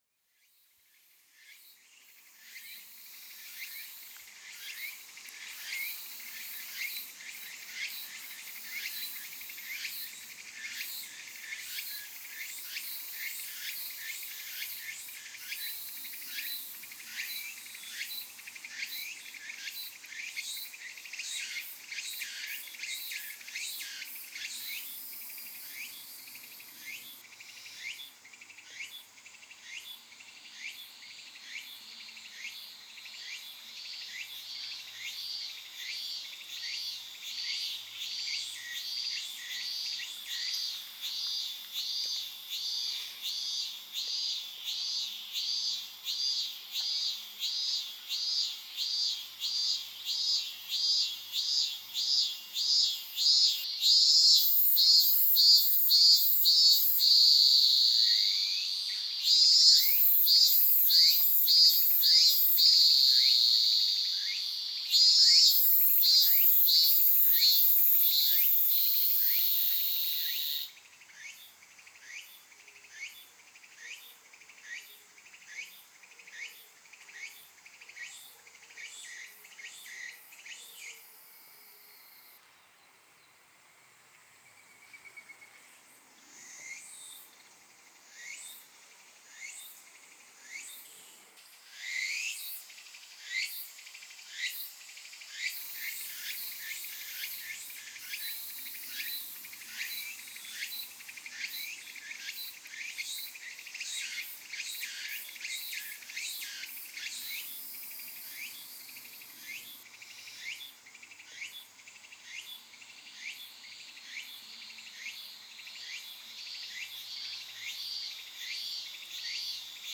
2025年8月中旬に茨城県戸頭でのツクツクボウシとミンミンゼミです。